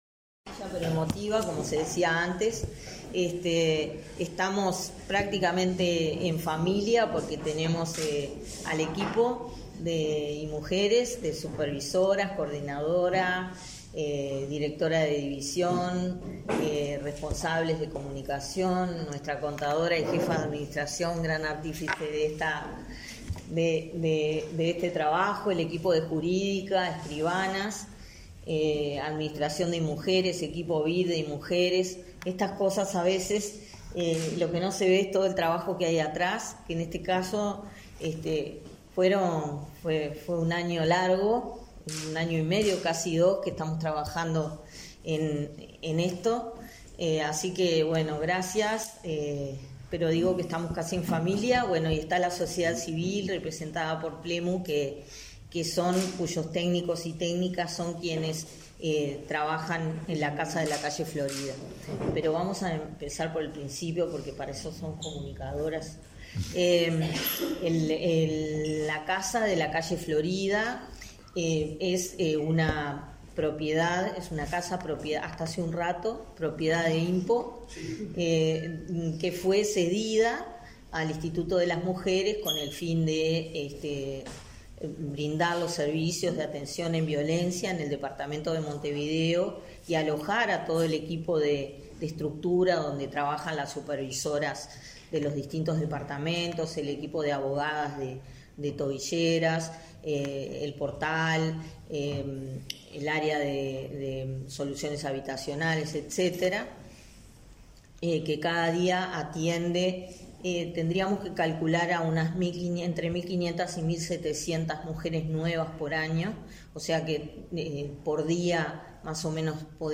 Conferencia de prensa por la cesión de vivienda para Inmujeres
Conferencia de prensa por la cesión de vivienda para Inmujeres 11/09/2023 Compartir Facebook X Copiar enlace WhatsApp LinkedIn El Ministerio de Desarrollo Social (Mides), a través de Instituto Nacional de las Mujeres (Inmujeres), y la Dirección Nacional de Impresiones Oficiales (IMPO) firmaron un acuerdo, este 11 de setiembre, por la donación de un edificio para el servicio de atención a mujeres en situación de violencia en Montevideo. Participaron el ministro Martín Lema; la directora de Inmujeres, Mónica Bottero, y director de Impo, Fernando Abellá.